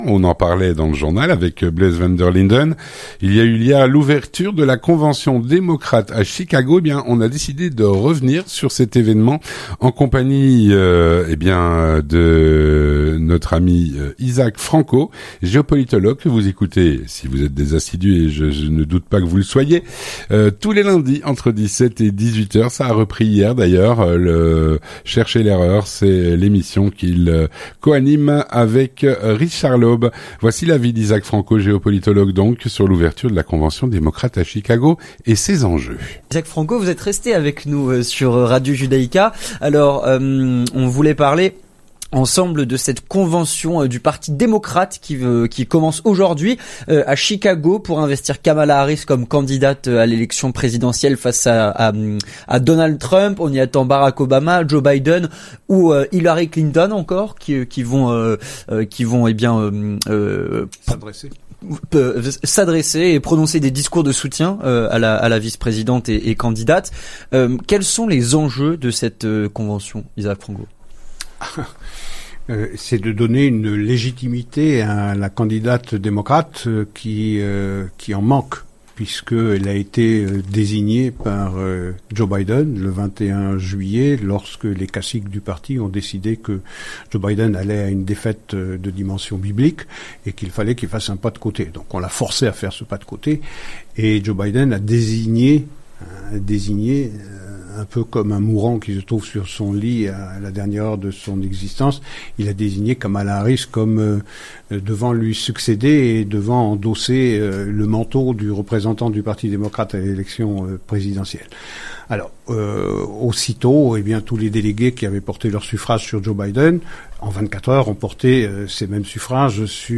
L'entretien du 18H - L’ouverture de la convention démocrate à Chicago.